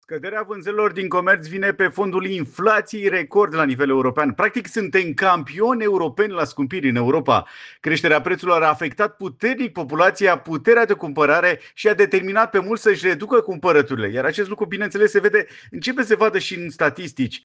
Analistul economic